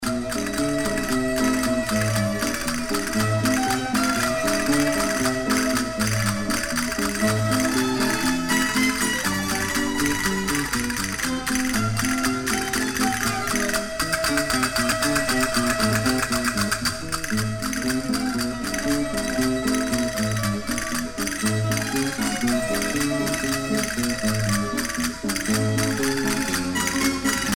danse : paso-doble